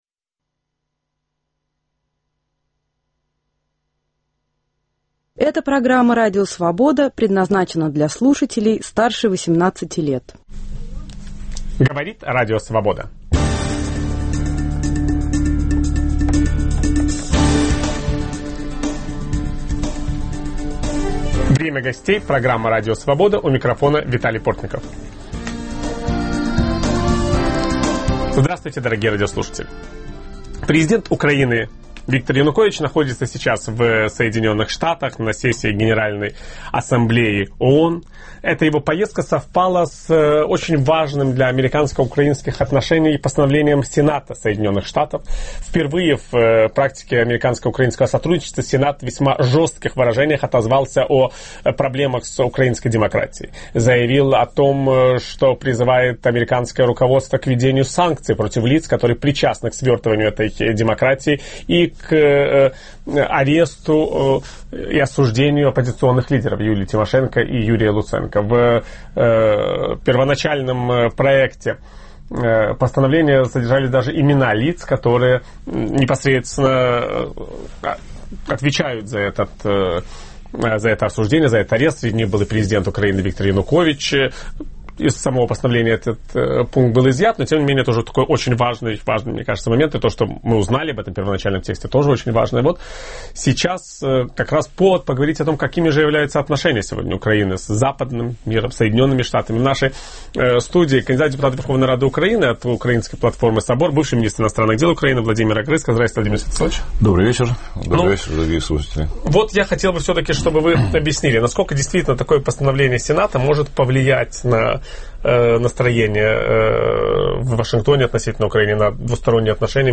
Возможны ли санкции против украинских политиков? В программе участвует бывший министр иностранных дел Украины Владимир Огрызко.